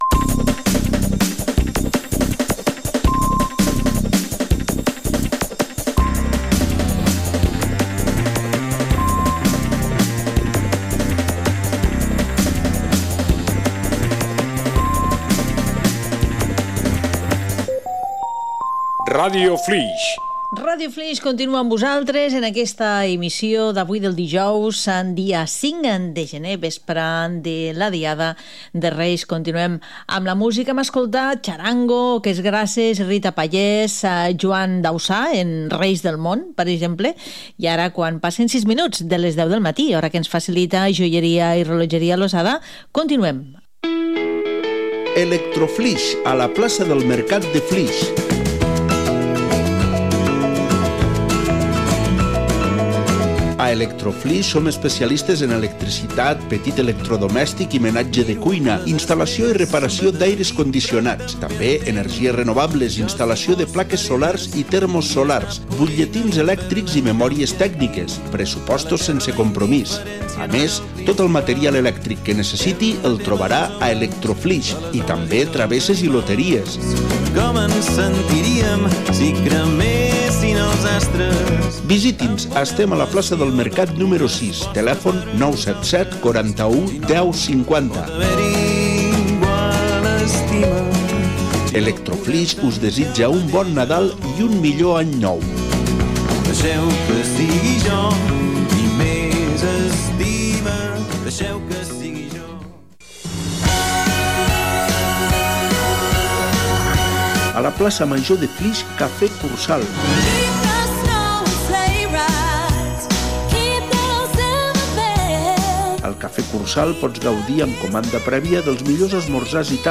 Identificació, data, temes musicals escoltats, publicitat i tema musical.
FM